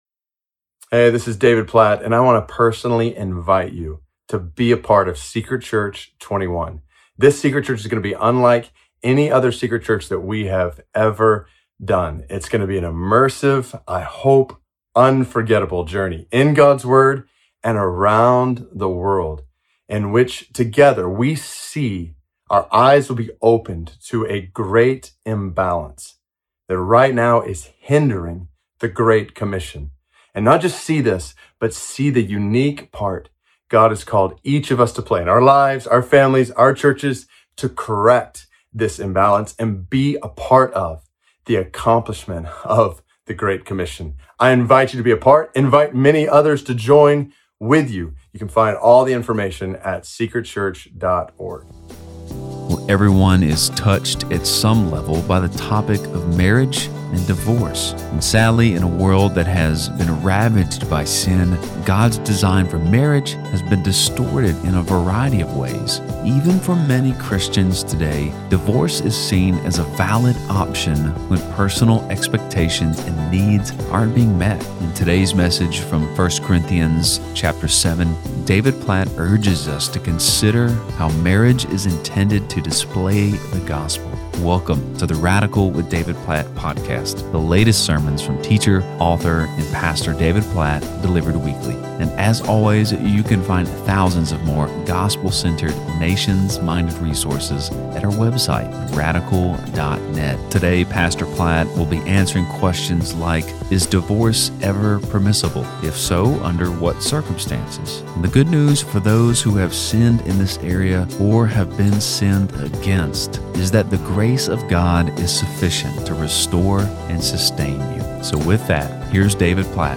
In this episode of the Radical Podcast on 1 Corinthians 7:10–16, David Platt teaches us how marriage is intended to display the gospel.